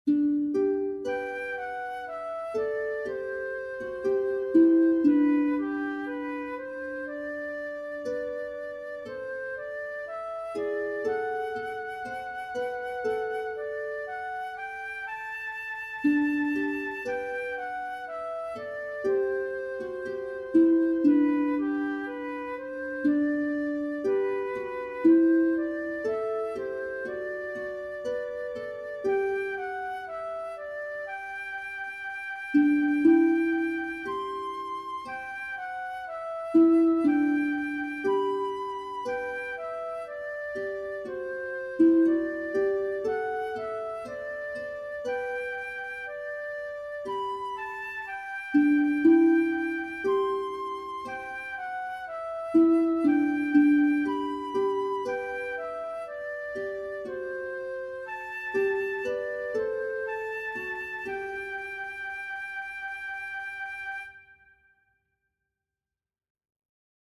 Featured Harp Music
MP3 Audio of Tune & Descant